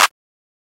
MZ Clap [RIP Screw] (1).wav